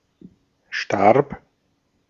Ääntäminen
Ääntäminen Tuntematon aksentti: IPA: /ʃtaʁp/ Haettu sana löytyi näillä lähdekielillä: saksa Käännöksiä ei löytynyt valitulle kohdekielelle. Starb on sanan sterben imperfekti.